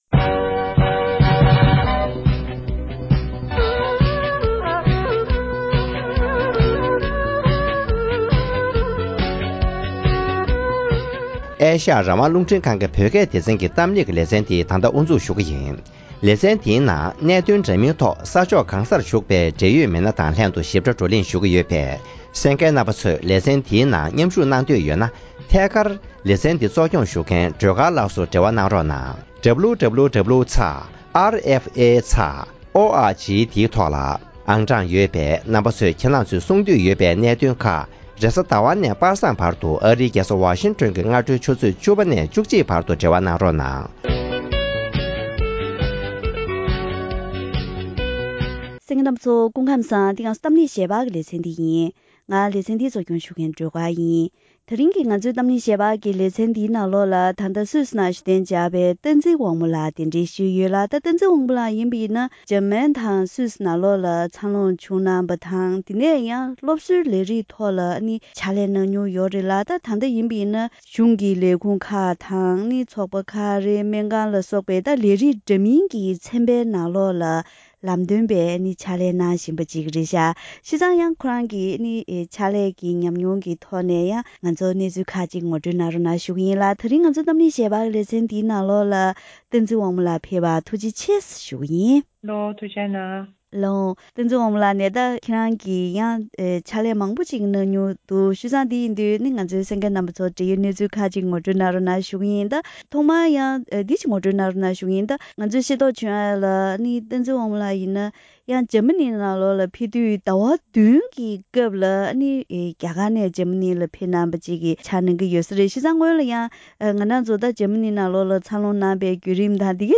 གཏམ་གླེང་ལེ་ཚན